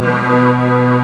STRINGB.M1C3.wav